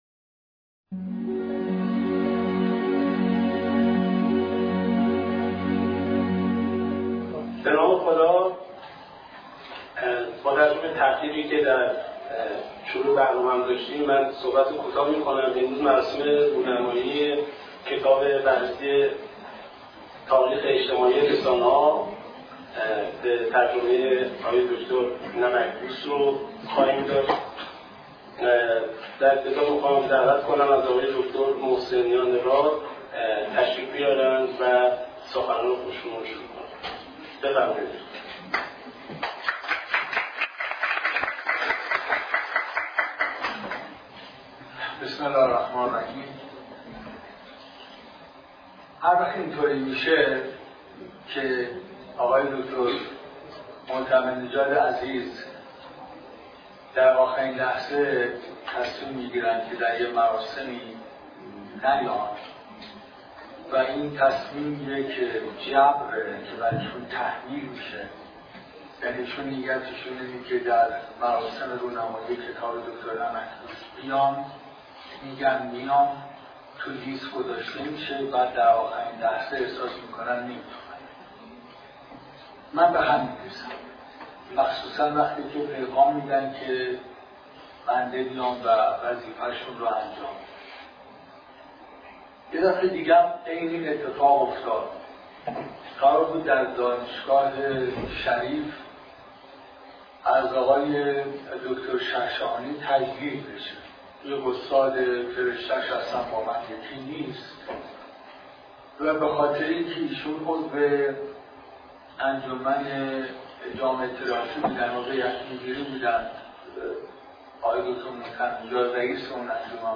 دهم آبان‌ماه به همت فرهنگستان هنر و در سالن همایش‌های فرهنگستان هنر برگزار شد. در این نشست تنی چند از استادان، صاحب‌نظران و دست‌اندکاران حوزه ارتباطات و رسانه‌ها به بررسی ابعاد و ویژگی‌های مختلف این کتاب پرداختند.